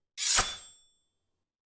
SWORD_17.wav